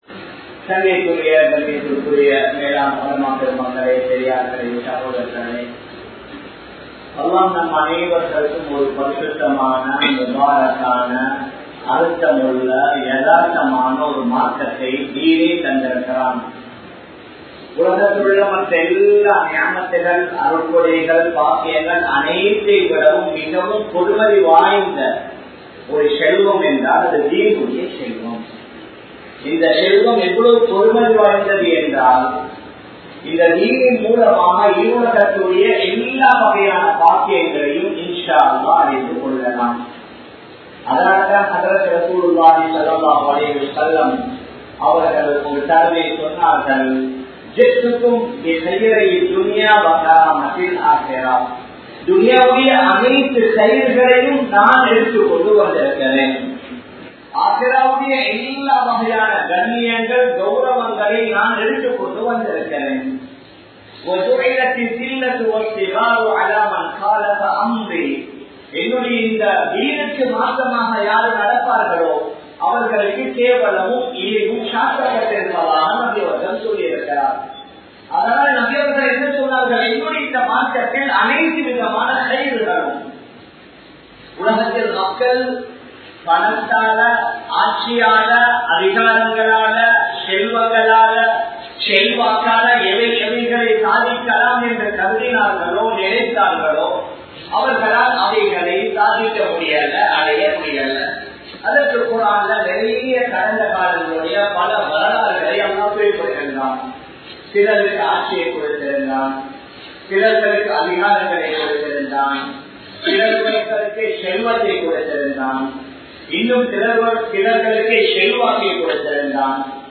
Eemanudaya Ulaippu Avasiyam Thevai!(ஈமானுடைய உழைப்பு அவசியம் தேவை!) | Audio Bayans | All Ceylon Muslim Youth Community | Addalaichenai